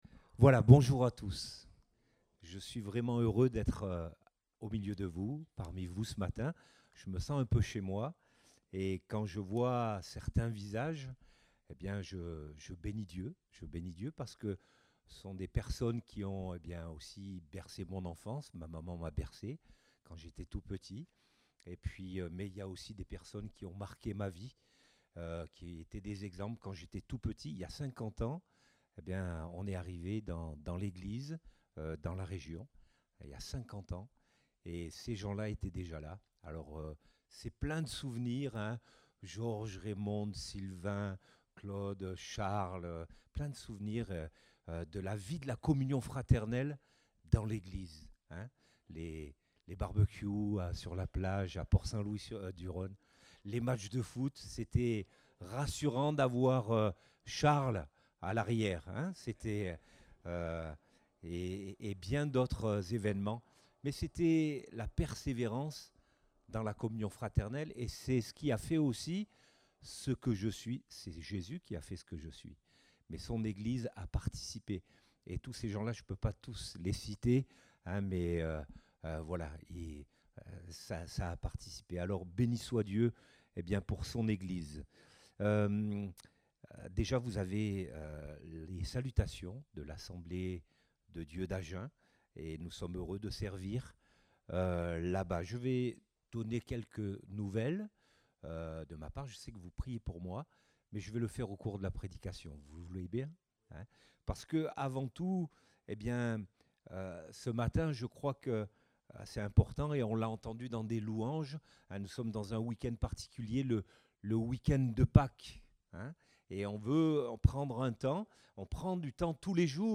Date : 9 avril 2023 (Culte Dominical)